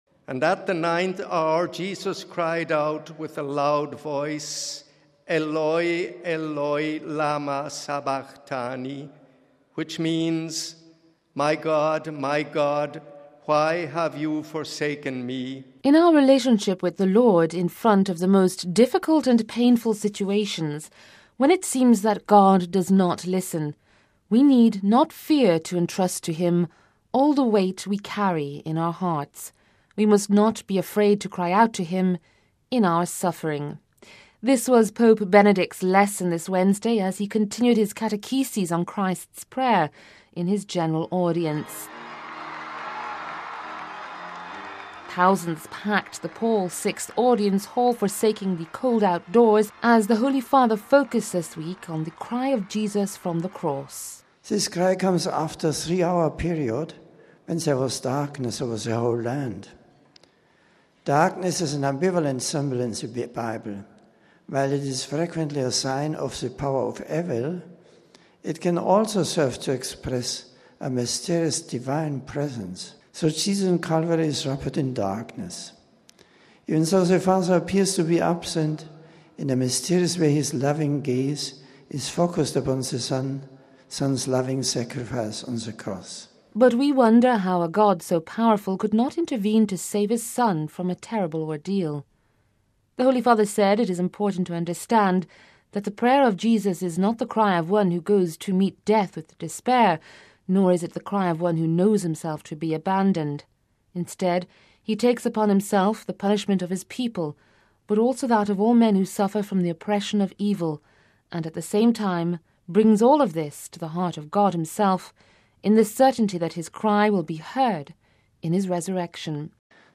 “In our relationship with the Lord, in front of the most difficult and painful situations, when it seems that God does not listen, we need not fear to entrust to Him all the weight we carry in our hearts, we must not be afraid to cry out to Him in our suffering”, said Pope Benedict XVI Wednesday as he continued his series of lessons on Christ’s prayer in his General Audience, this week focusing on the cry of Jesus from the Cross: “My God, my God, why have you forsaken me?”.